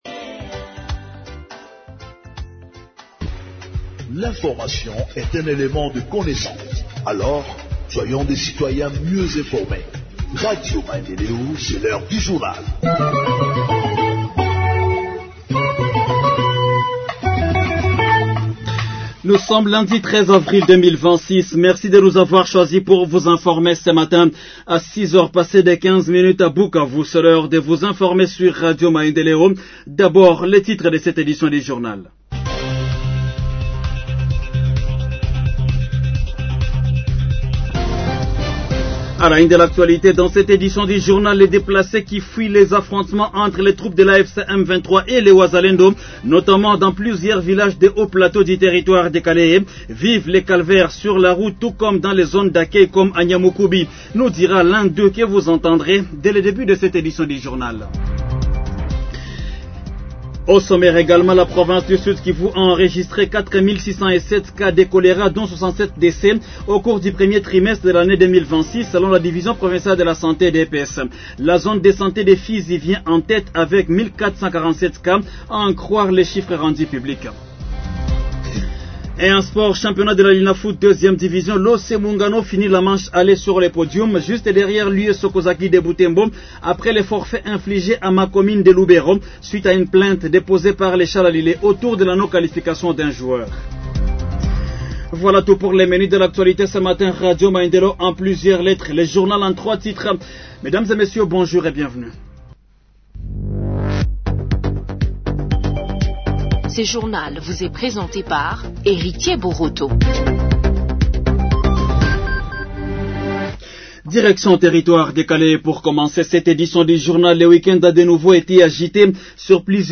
Journal en Français du 13 Avril 2026 – Radio Maendeleo